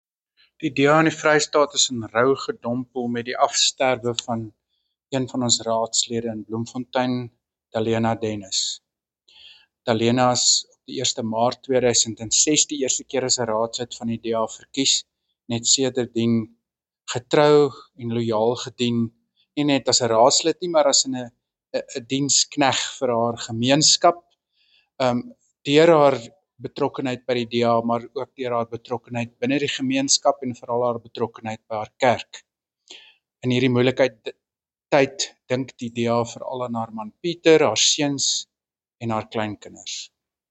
Afrikaans soundbites by Werner Horn MP and Sesotho soundbite by Jafta Mokoena MPL.